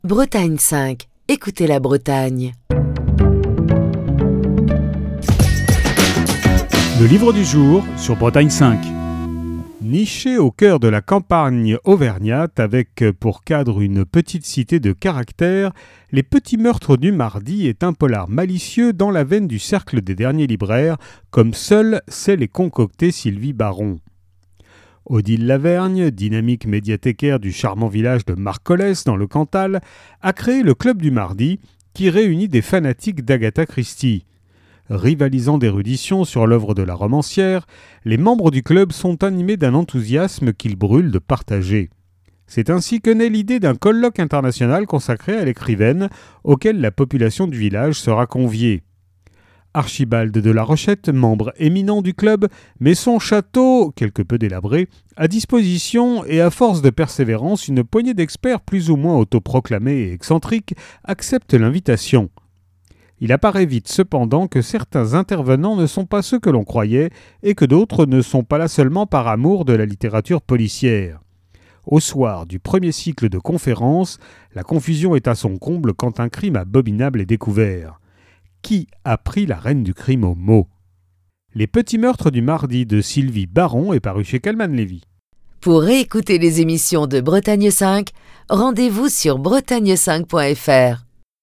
Chronique du 2 mars 2023.